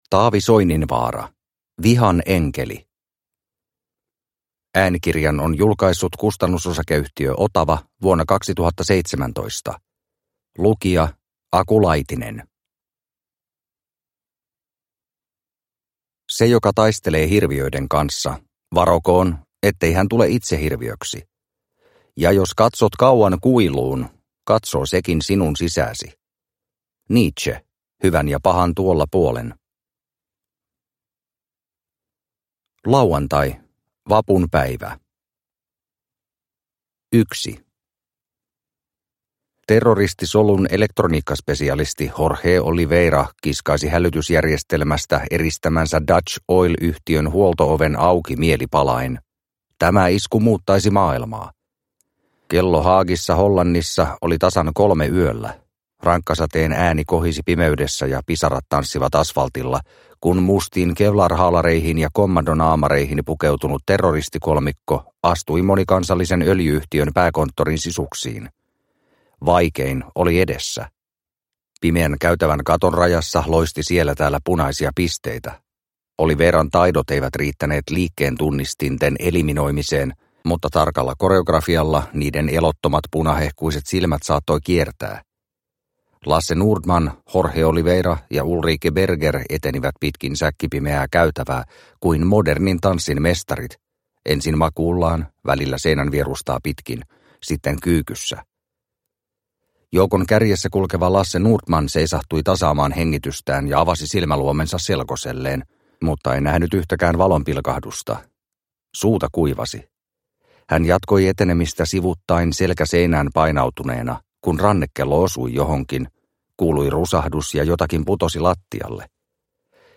Vihan enkeli – Ljudbok – Laddas ner